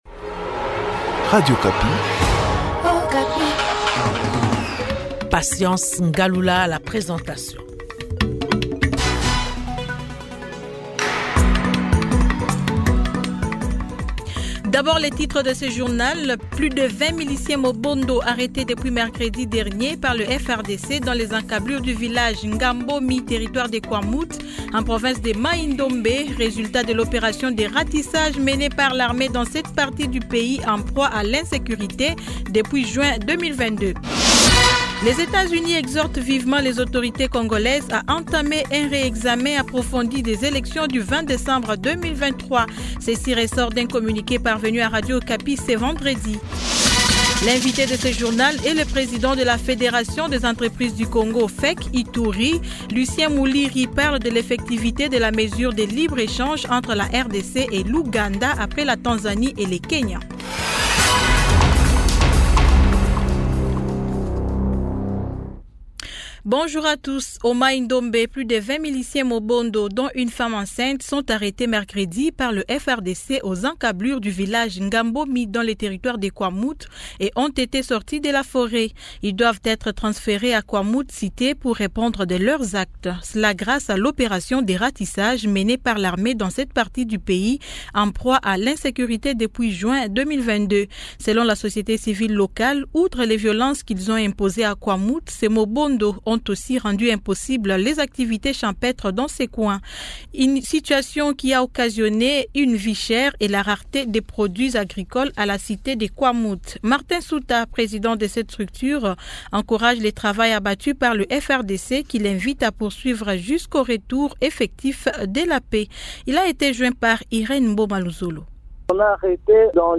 Journal 15H00